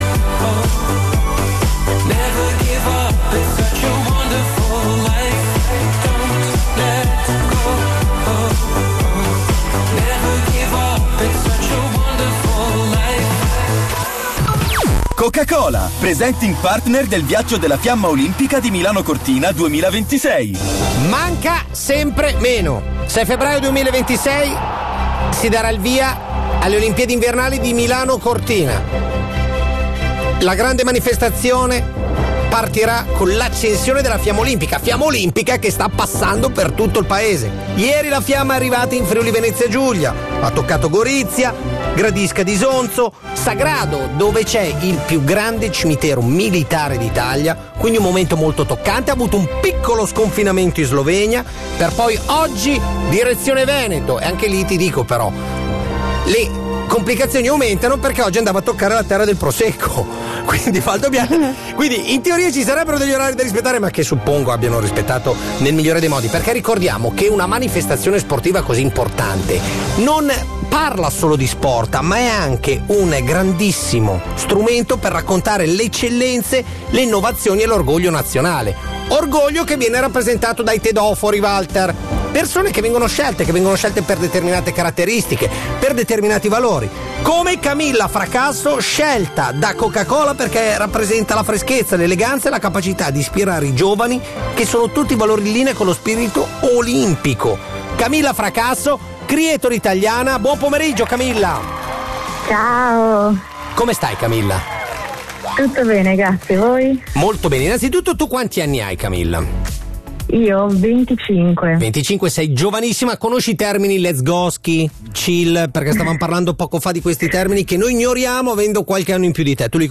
Ai microfoni di Radio 105 si è raccontata così:
INTERVISTE